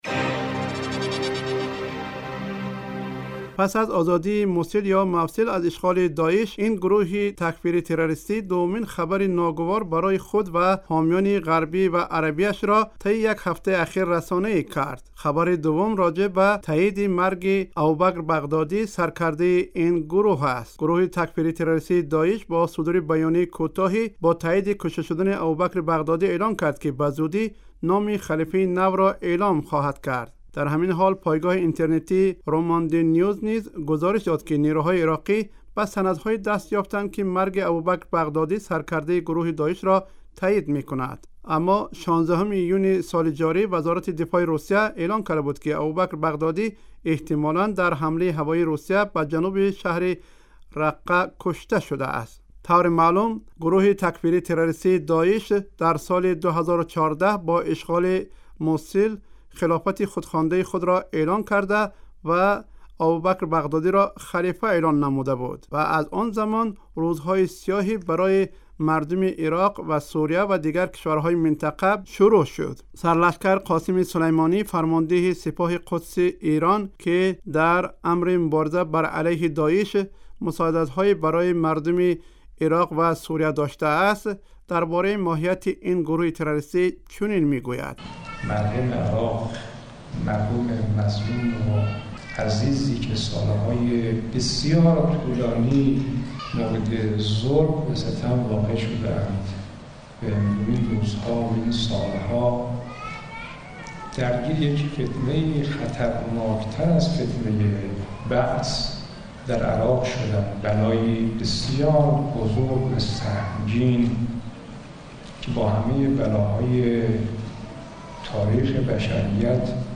گزارش ویژه؛ احتمال انتقال گروه تروریستی داعش به مرزهای تاجیکستان